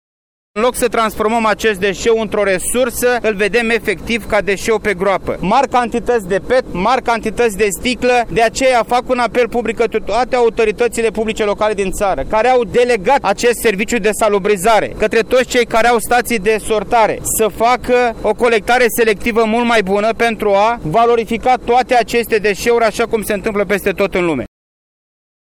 Colectați selectiv gunoaiele și reciclați. Este apelul pe care l-a făcut ministrul Mediului, Costel Alexe, aflat în vizită, la Brașov, la Depozitul Ecologic de Deșeuri, unde a vizitat un front de lucru de 2500 demetri la celula 3, în care se depozitează gunoaiele adunate de la populație.
Ministrul Mediului, Costel Alexe: